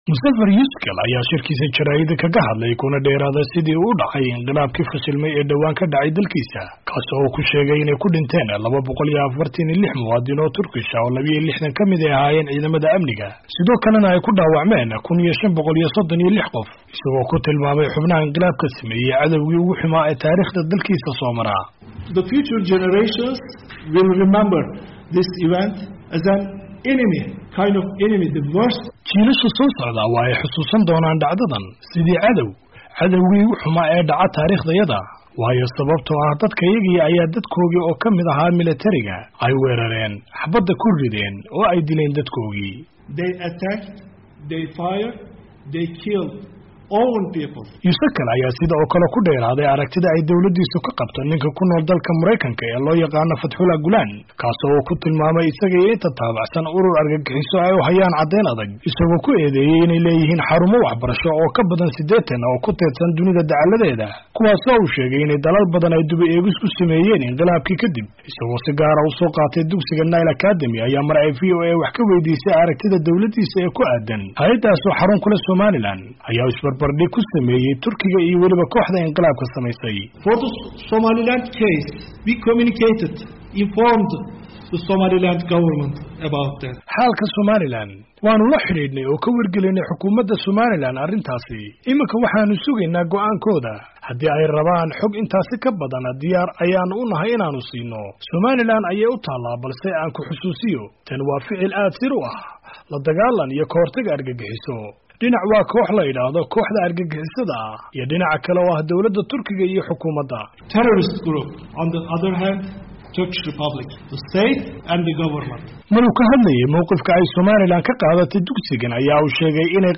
Daawo-Dhagayso: Qunsulka Turkiga ee Hargeysa oo ka Hadlay inay Nile Academy ka dambaysay Inqilaabkii fashilmay!!